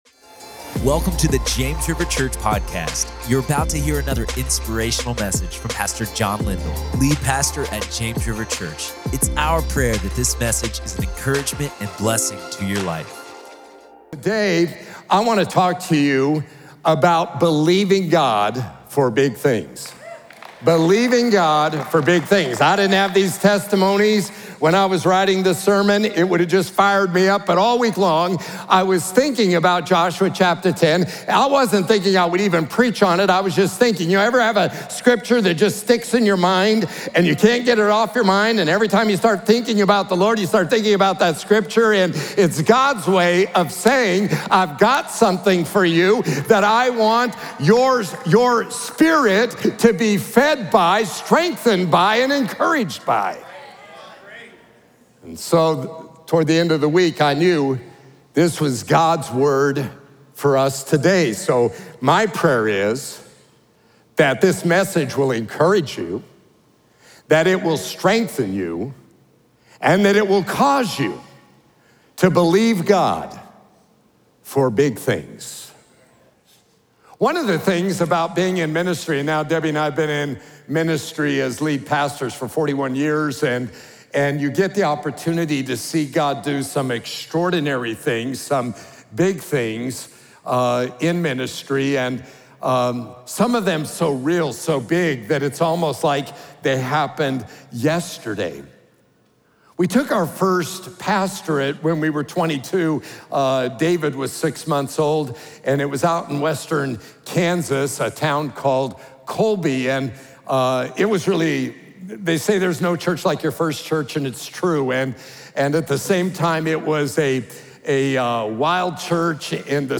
In this faith-filled message